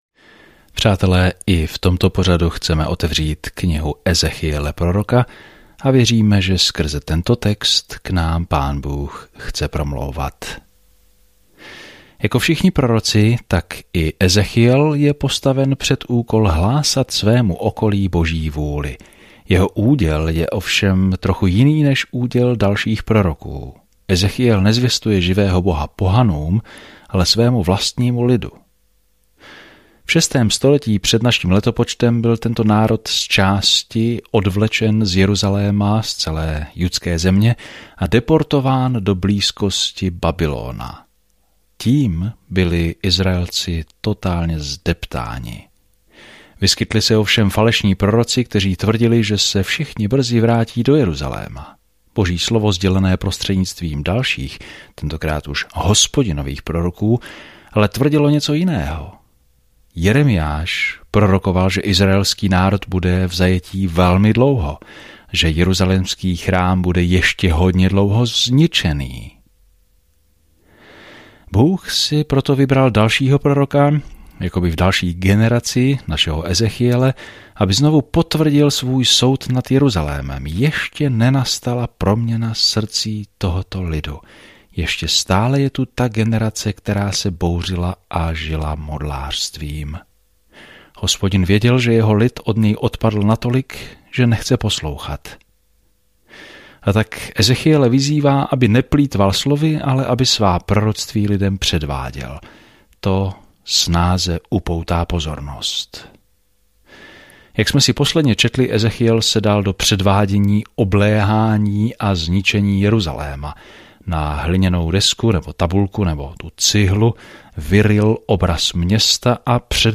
Písmo Ezechiel 4:9-17 Ezechiel 5 Ezechiel 6:1-8 Den 3 Začít tento plán Den 5 O tomto plánu Lidé nechtěli naslouchat Ezechielovým varovným slovům, aby se vrátili k Bohu, a tak místo toho předvedl apokalyptická podobenství a to probodlo srdce lidí. Denně procházejte Ezechielem a poslouchejte audiostudii a čtěte vybrané verše z Božího slova.